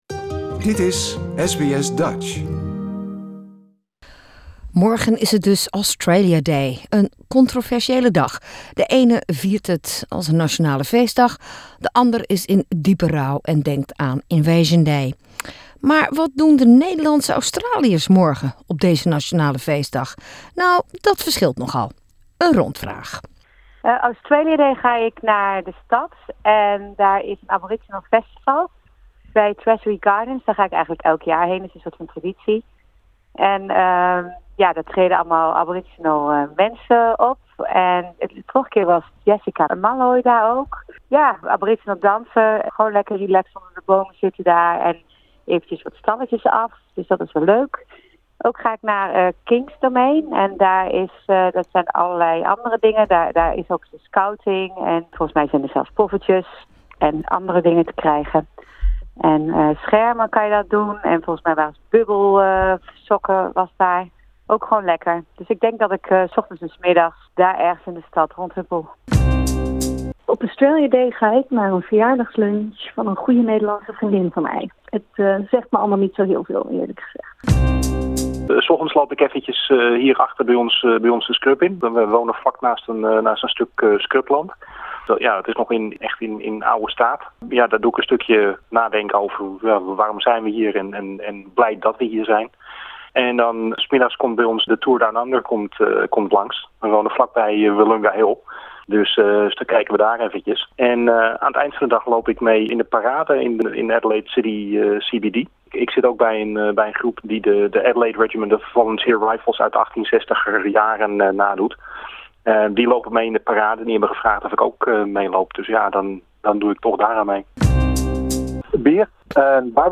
Australia Day, loop je mee in een optocht, demonstreer je tegen Invasion Day of ga je lekker de hele dag Netflixen? Een rondvraagje...